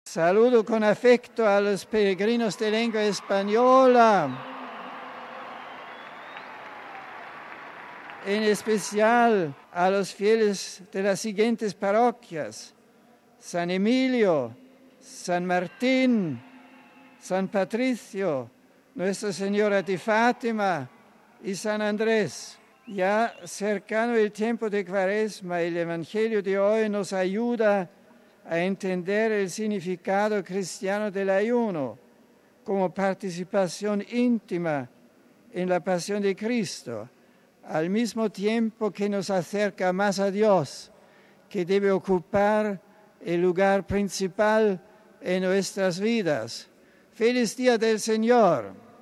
Domingo, 26 feb (RV).- Un domingo más Benedicto XVI se ha asomado a la ventana de su despacho privado en el Palacio Apostólico del Vaticano para dirigir el rezo del Ángelus ante miles de fieles congregados en la plaza de San Pedro.
Y tras el rezo mariano del Ángelus, Benedicto XVI ha saludado en varias lenguas recordando que el próximo Miércoles de Ceniza da inicio el tiempo de Cuaresma. Éstas han sido sus palabras en español.